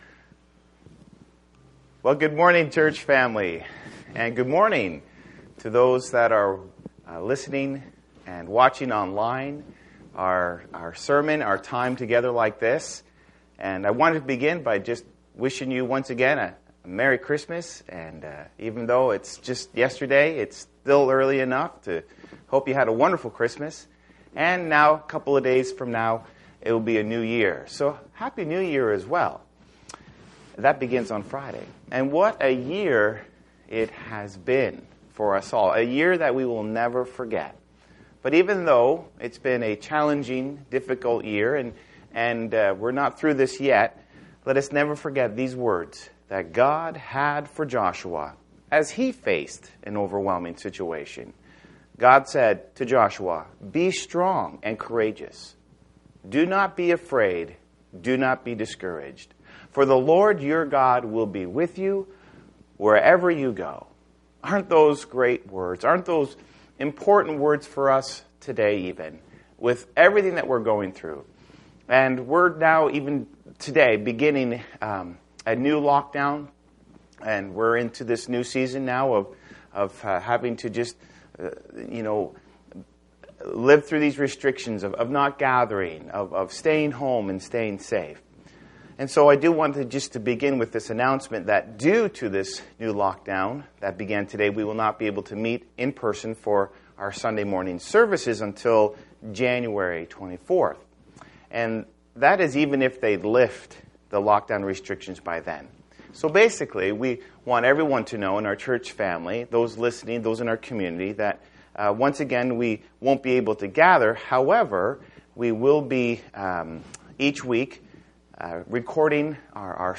Audio/Video Sermons